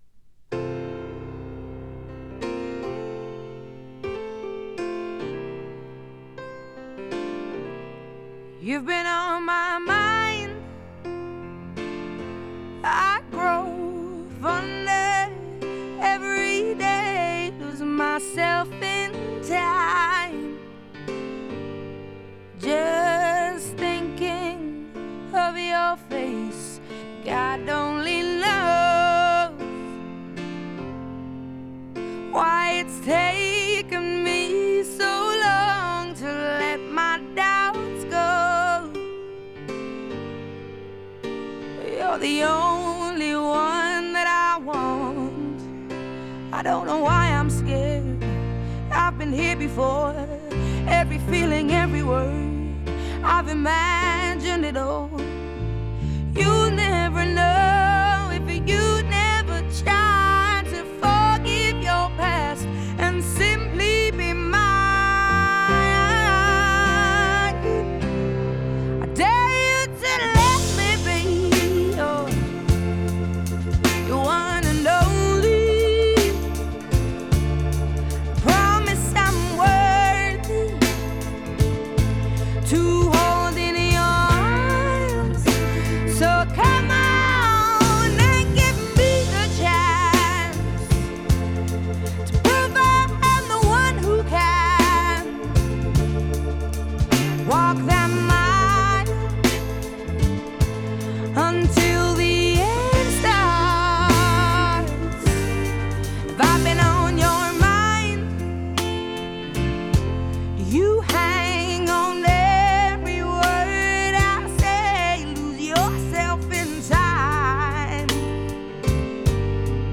Genre: Pop, Blues